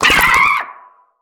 Sfx_creature_penguin_flinch_land_03.ogg